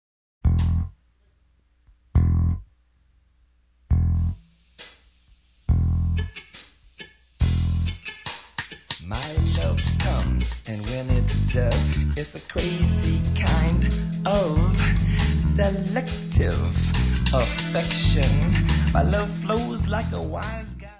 Music Category/Genre:  Pop / Rock